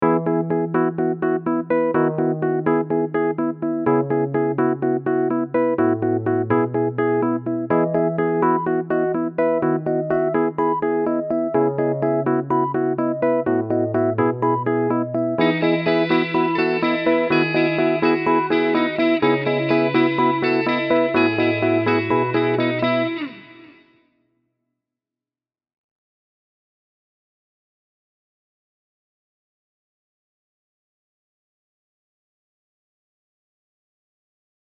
●イントロ
3つのリフが鳴っています。
1.ウーリッツァー（鍵盤楽器）
2.柔らかい印象のピコピコサウンド
3.クリーンなエレキギターリフ
分かりやすく4小節ごとに1~3の順で音を増やしています。